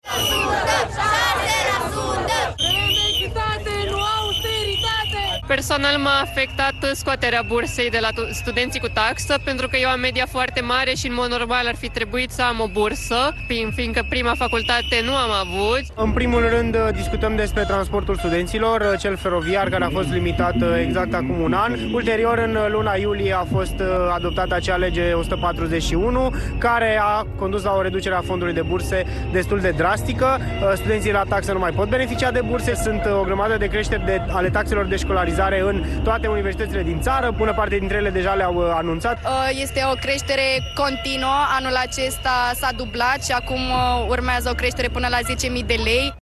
Contestă măsurile de austeritate din Învățământ, în special tăierea unor burse pentru studenți și reducerea transportului gratuit pentru studenți, după cum au declarat la postul public de televiziune.
15mar-09-VOX-Studentii-de-la-Guvern.mp3